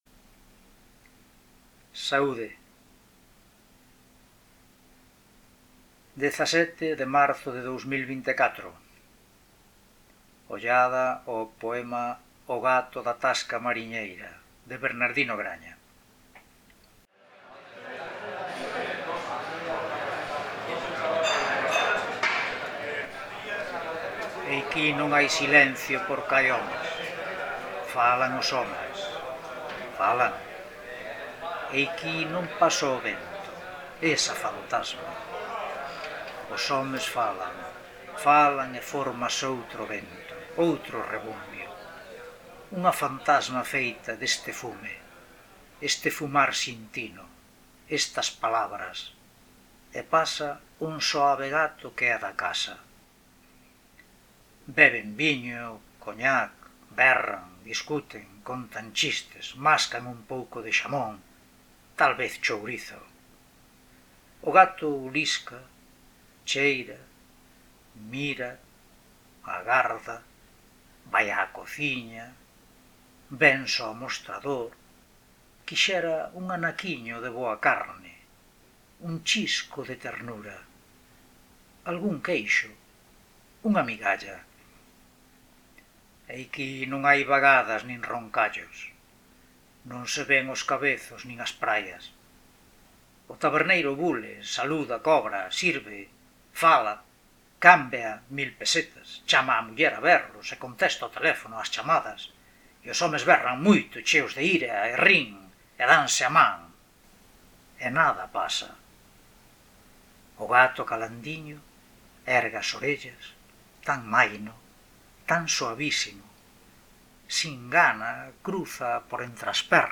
Lecturas de poemas